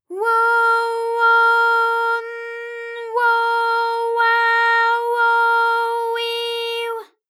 ALYS-DB-001-JPN - First Japanese UTAU vocal library of ALYS.
wo_wo_n_wo_wa_wo_wi_w.wav